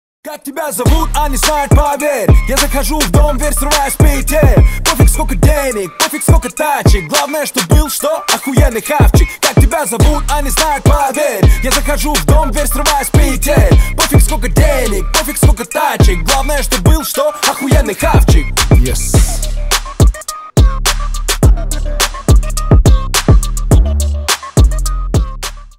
Жанр: Русский рэп